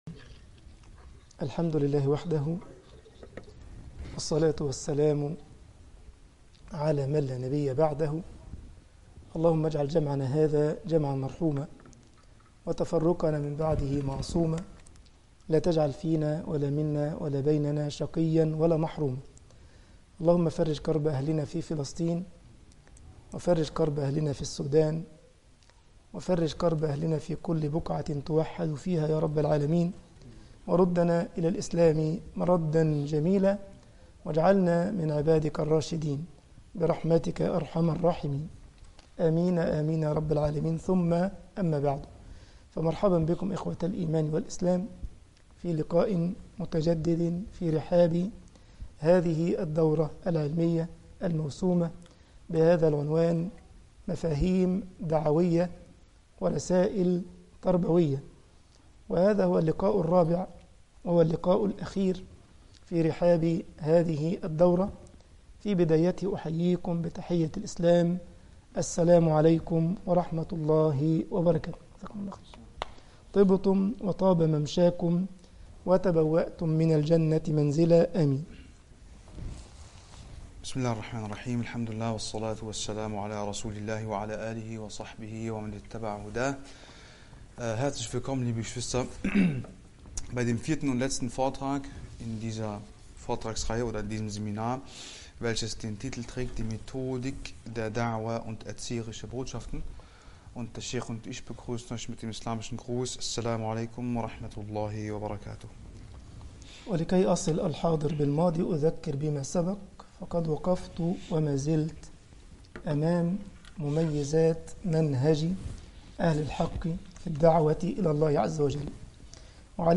دورة علمية بعنوان مفاهيم دعوية ورسائل تربوية المحاضرة 4 طباعة البريد الإلكتروني التفاصيل كتب بواسطة